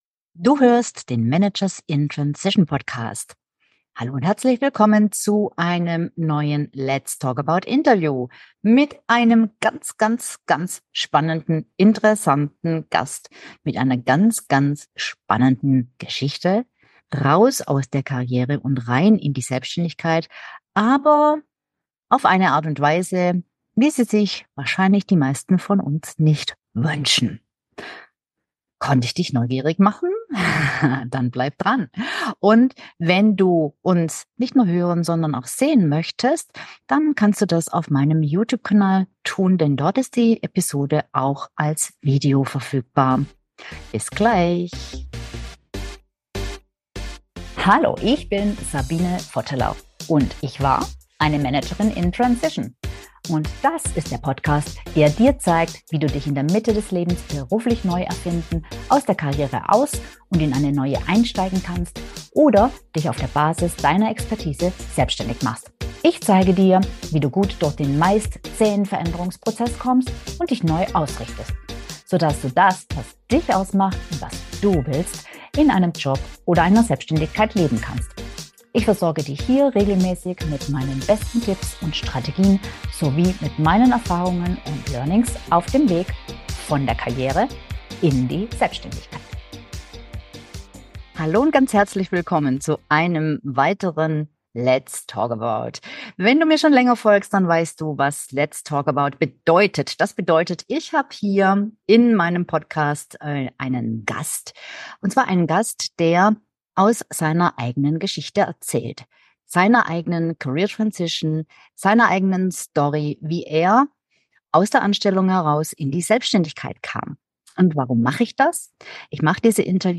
Seine Krebserkrankung führte ihn in die Selbstständigkeit. Wie ihm das gelang, erzählt er im Interview.